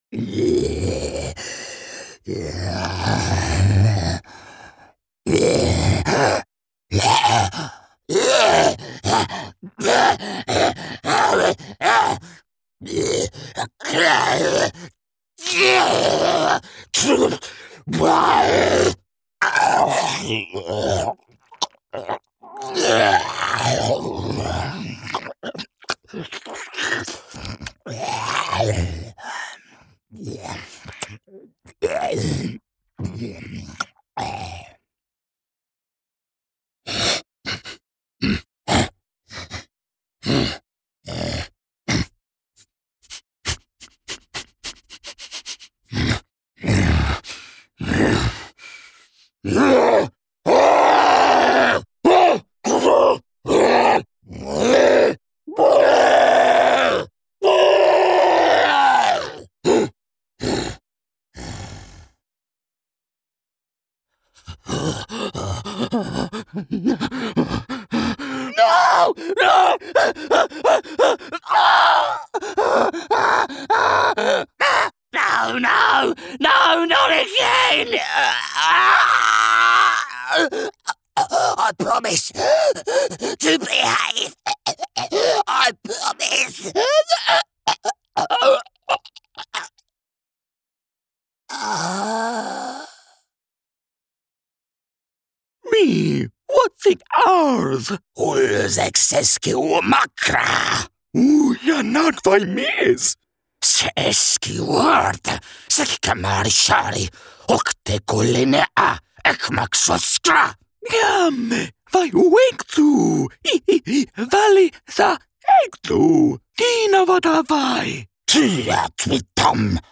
Creature/Non-Human Showreel
Male
East Midlands
Confident
Friendly
Reassuring